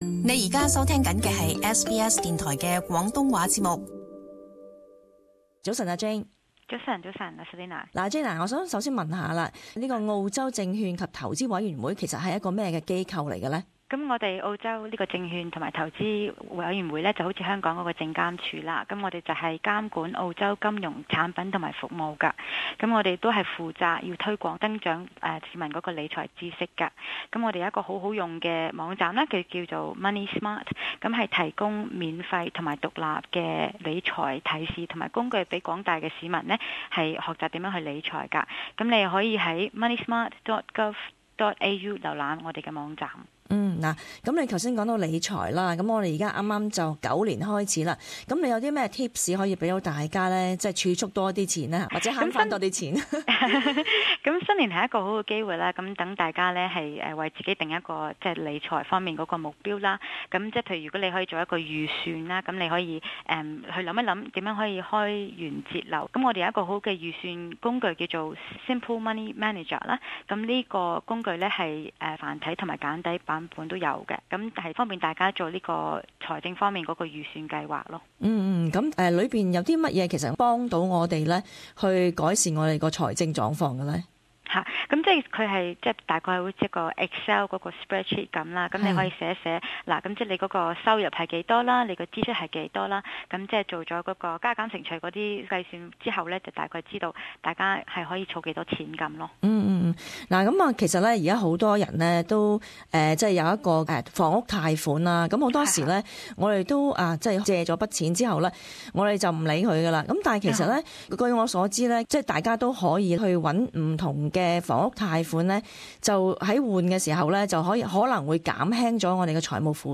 【社團專訪】：澳洲證券及投資委員會職能